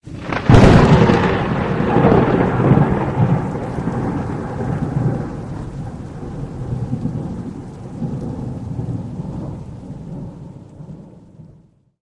thunder_18.ogg